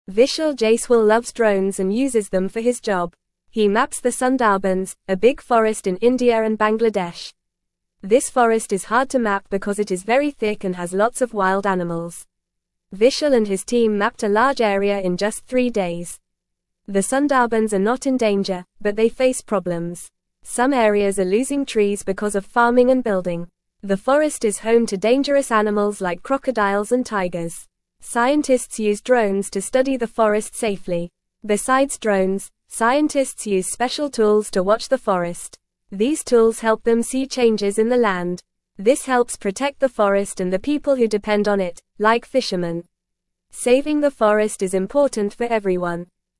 Fast